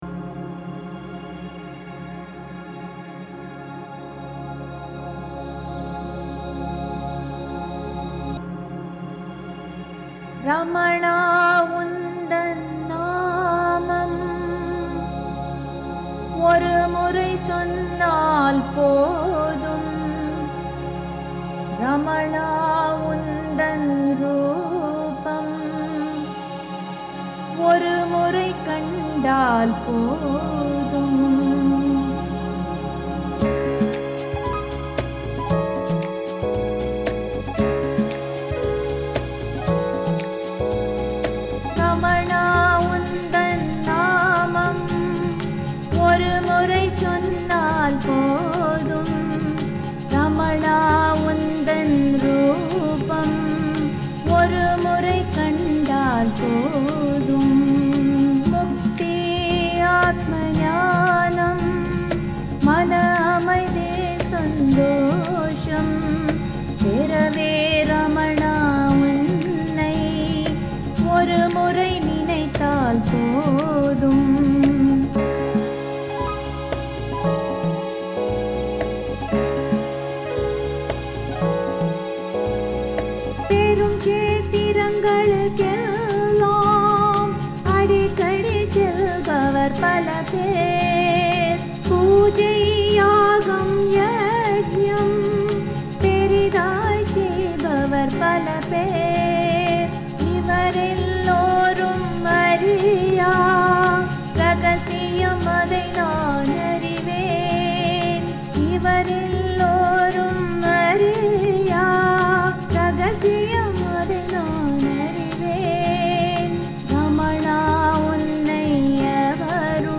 My Song Dedication in TAMIL To Ramana Maharshi, The Sage Of Sages, The Guru Of Gurus